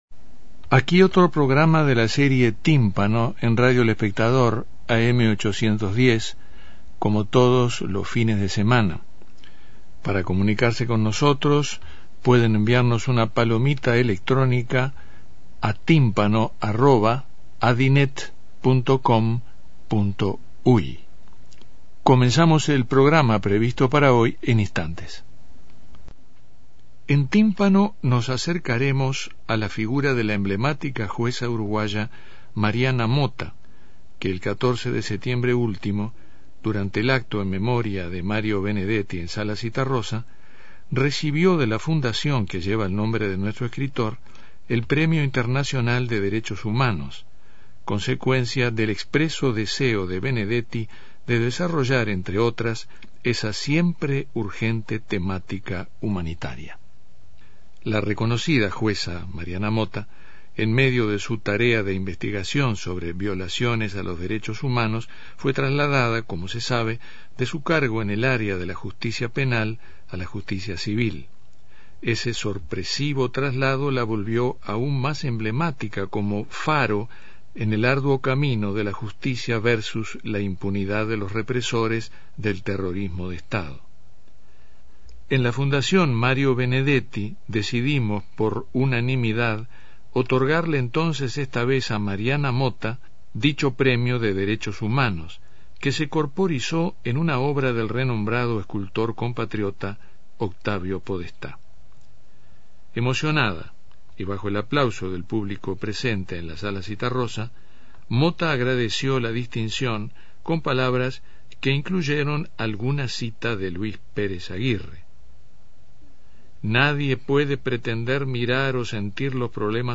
Daniel Viglietti entrevista a la reconocida Jueza uruguaya que en este2014 recibiera el Premio Internacional de Derechos Humanos "Mario Benedetti"